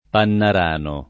[ pannar # no ]